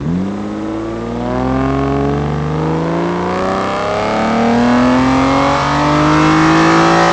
rr3-assets/files/.depot/audio/Vehicles/v12_01/v12_01_Accel.wav
v12_01_Accel.wav